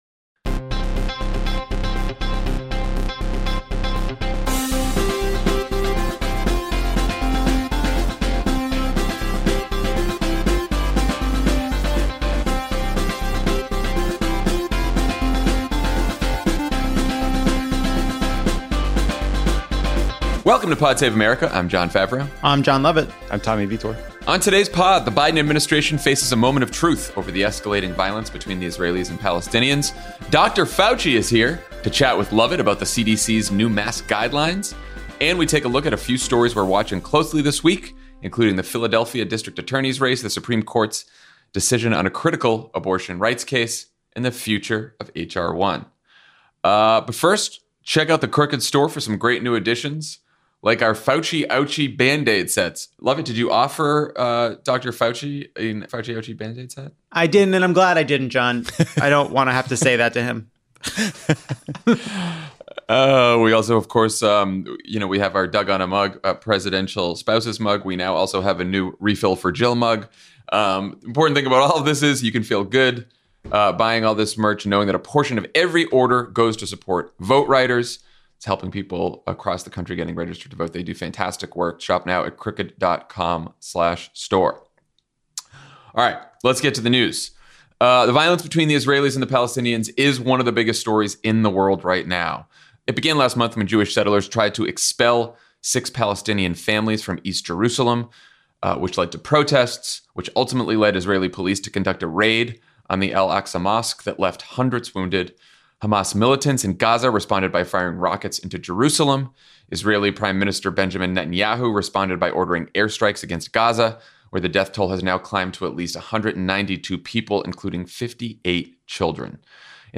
The Biden Administration faces a moment of truth over the escalating violence between Israel and Palestine, Dr. Anthony Fauci talks to Jon Lovett about the CDC’s new mask guidelines, and a look at some important stories to watch this week, including the Philadelphia District Attorney’s race, the Supreme Court’s decision to hear a case on Mississippi’s abortion ban, and the future of HR1.